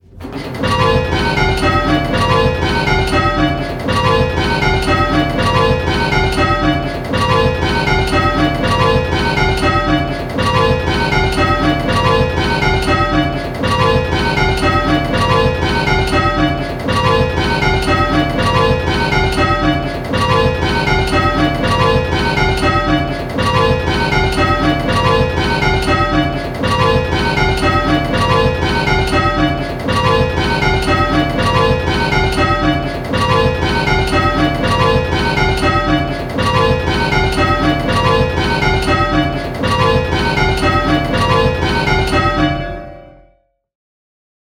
Hearing 6 bells...
We know that hearing specific bells can be difficult, so in these clips we have kept things very simple… in each clip 1 bell is ringing early… and consistently so, at both hand and backstroke.
Click the play button and listen to the rhythm of the bells…. the rhythm will sound a bit lumpy!
Rounds-2-early.m4a